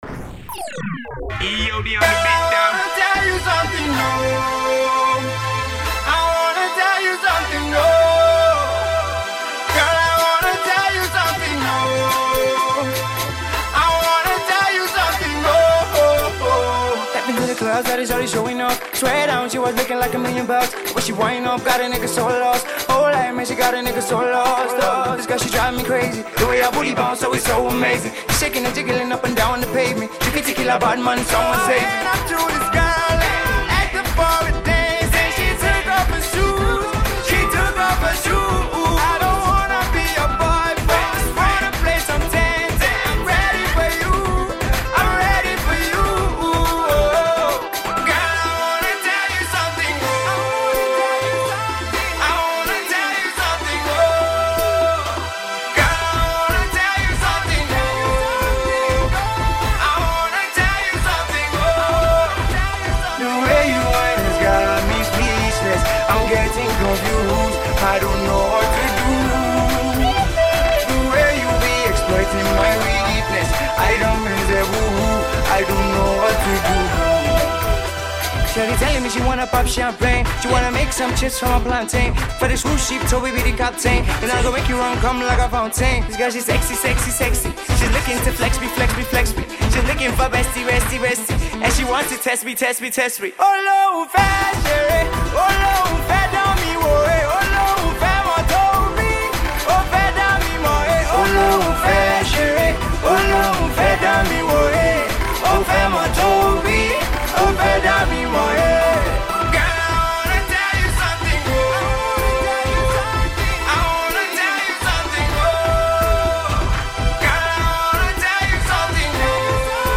He sounds the tiniest bit like Wande Coal